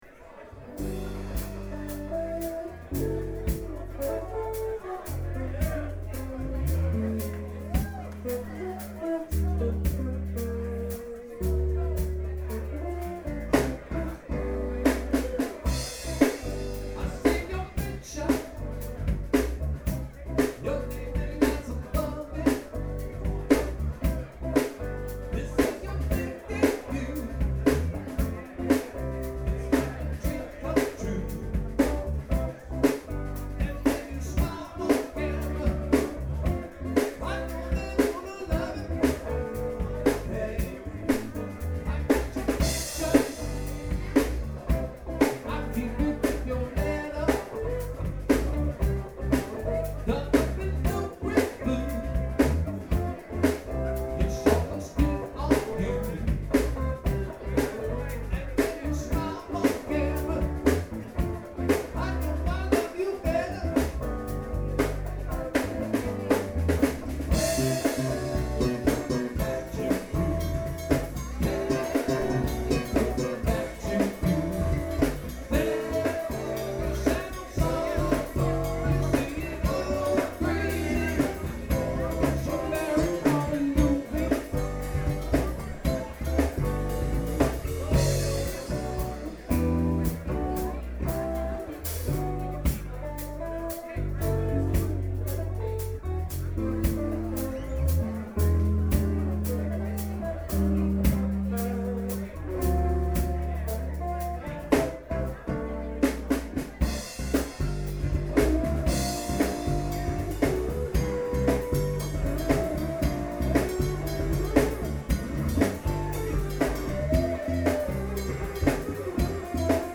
SPECIAL JAM